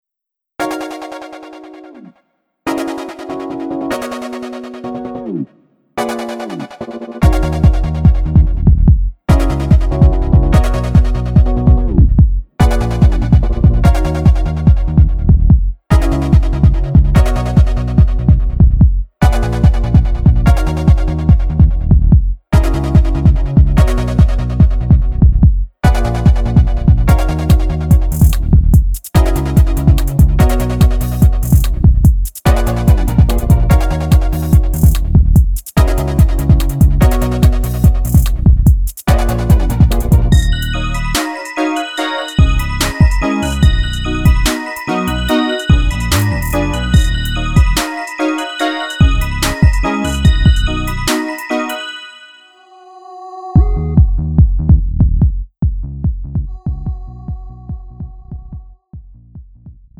고음질 반주 다운로드.
음정 원키
장르 가요